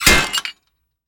На этой странице собраны реалистичные звуки капканов разных типов: от резкого металлического щелчка до глухого захлопывания.
Звуки капкана: Резкий звук сработавшего капкана